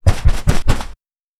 Punching Bag Powerful B.wav